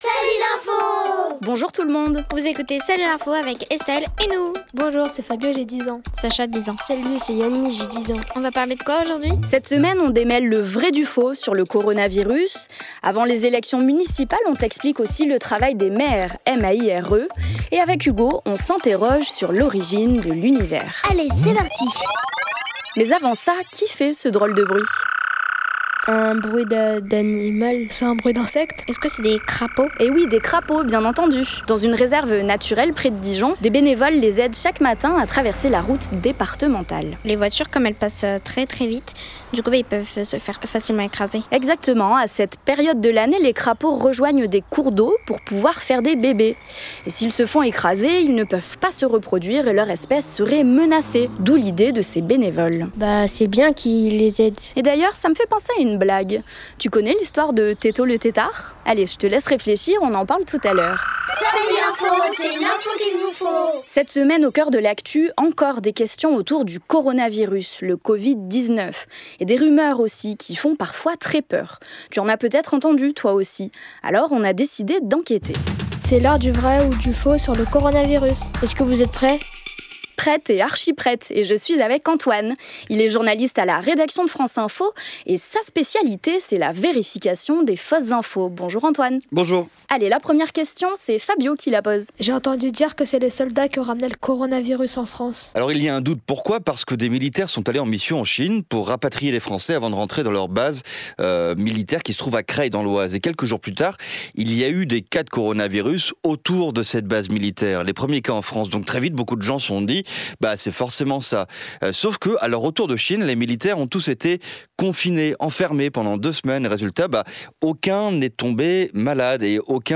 Le son : des crapauds dans une réserve naturelle près de Dijon.